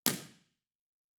Loudspeaker: Isobel Baritone Half-dodec
Microphone: Sennheiser Ambeo
Source: 14 sec log sweep
Playback RIR:
Test Position 3 – 20 m – XYStereo
RIR_TP3_Isobel_Ambio_20m_B4_XYSTEREO.wav